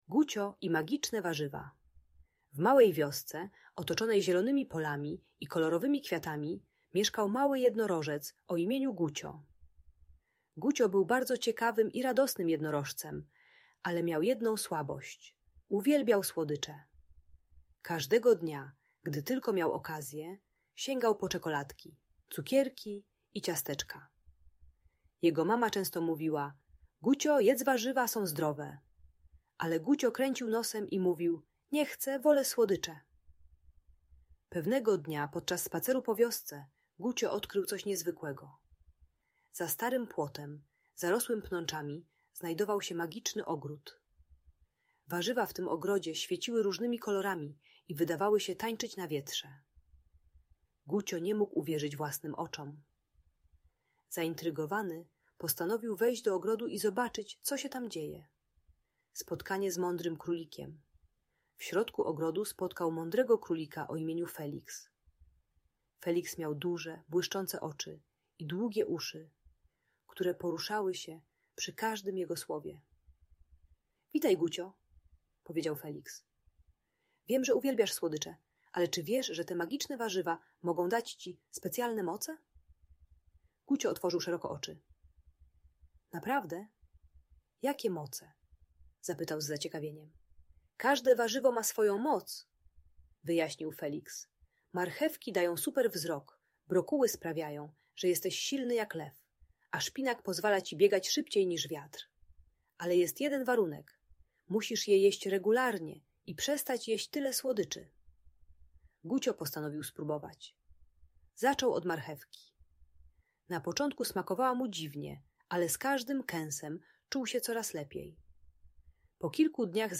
Gucio i Magiczne Warzywa - Audiobajka